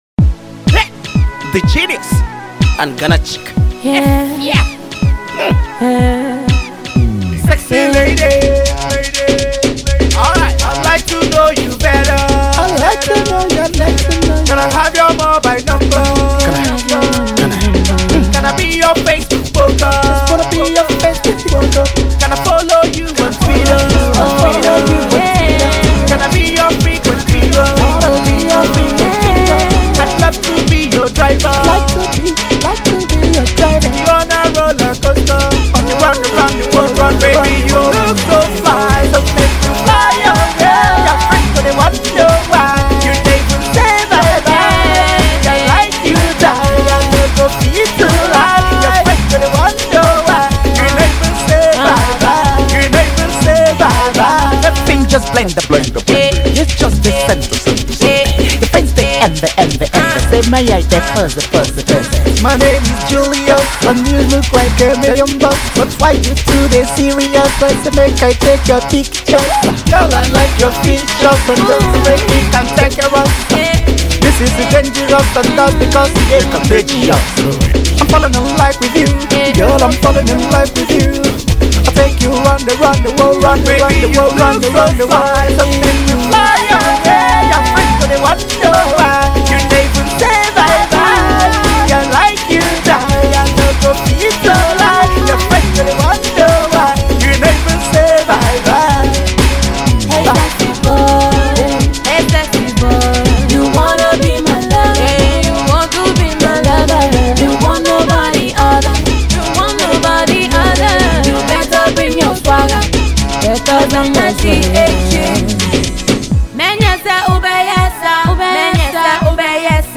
Afro-Dance vibes